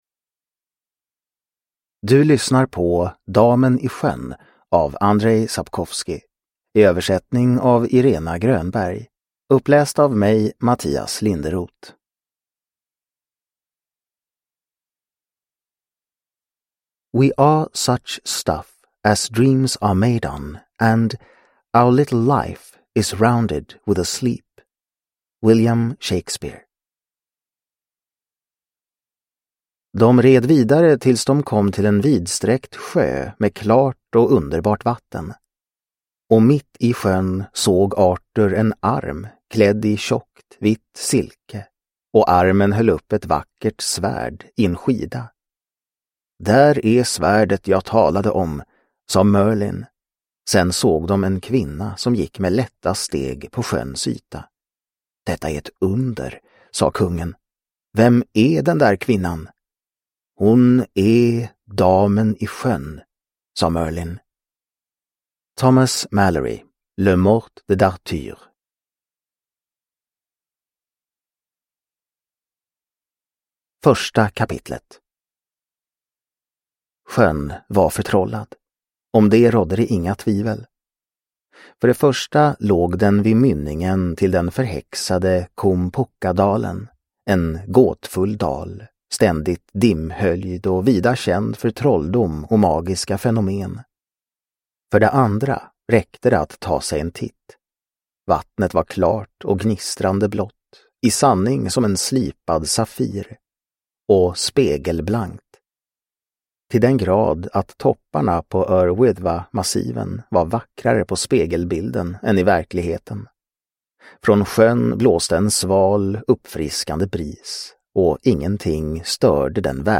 Damen i sjön – Ljudbok